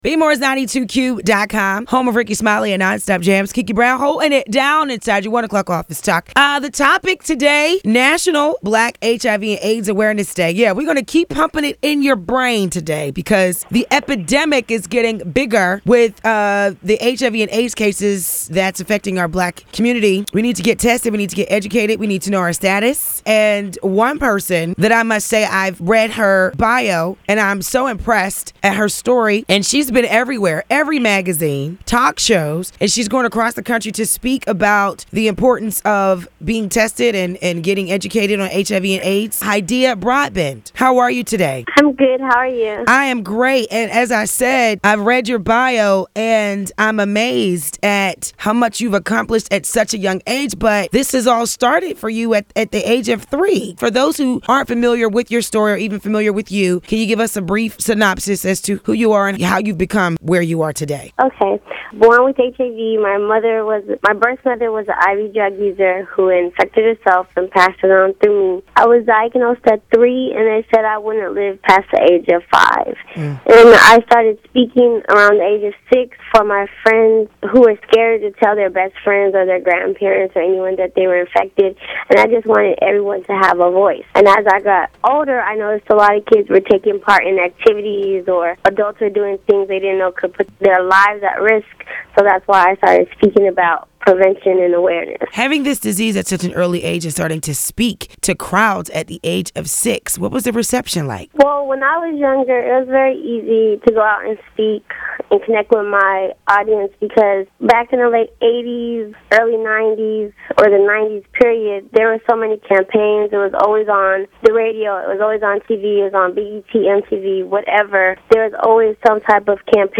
Last year, I had the pleasure of talking with a brilliant, beautiful and inspirational young lady by the name of Hydeia Broadbent.